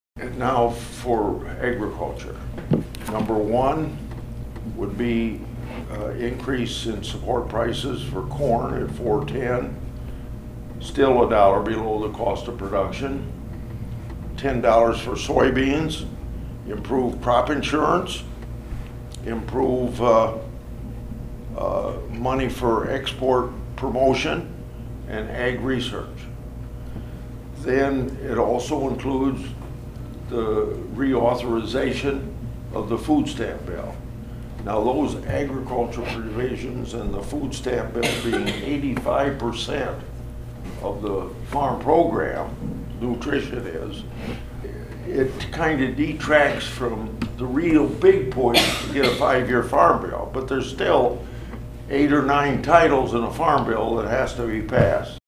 (Atlantic) Senator Chuck Grassley met with members of the Cass County Farm Bureau and others in the Ag community at TS Bank in Atlantic Friday afternoon.